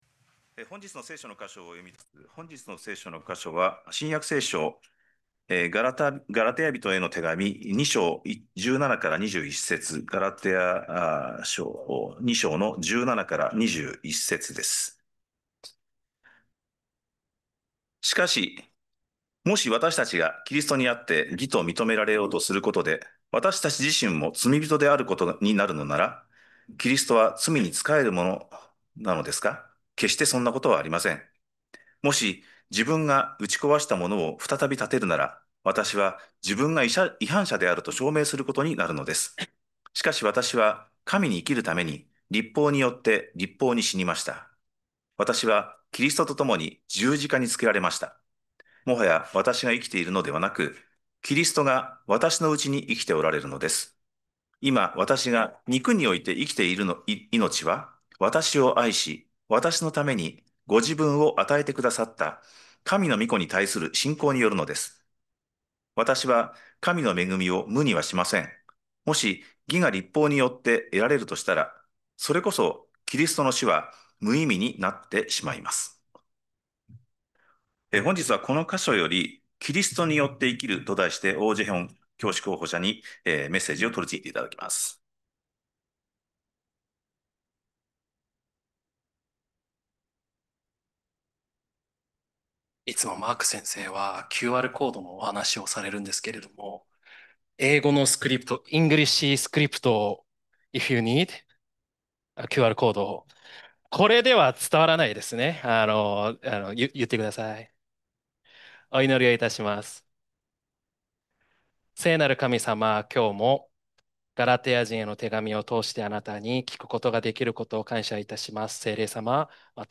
礼拝式順